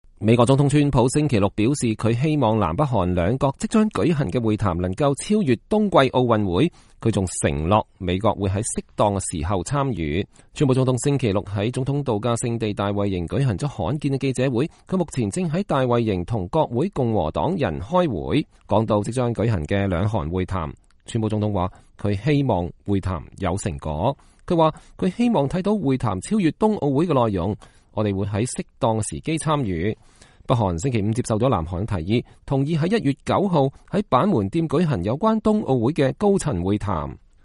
川普總統星期六在總統渡假地大衛營舉行了罕見的記者會，他目前正在大衛營與國會共和黨人開會。